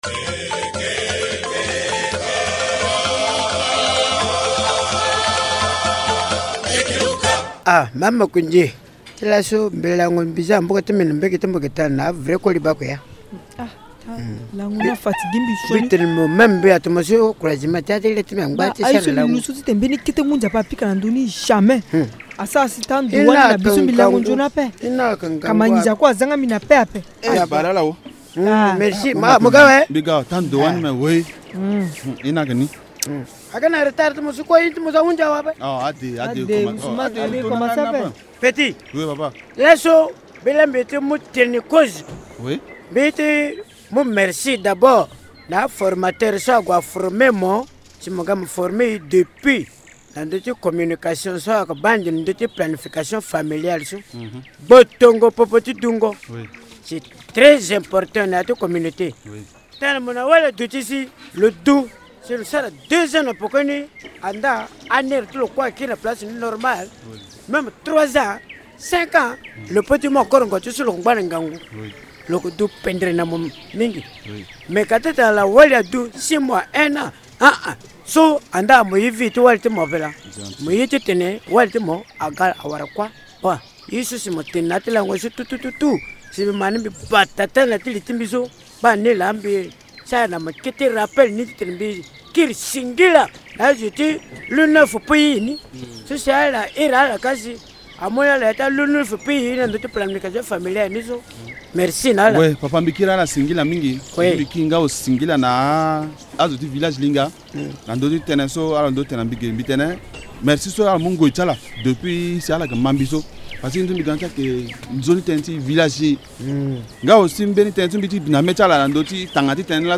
Les comédiens du village Linga estiment que l’homme et la femme ont le même droit en ce qui concerne la planification familiale.
Sketch Linga UNFPA Réduction  mortalité Maternelle 5.MP3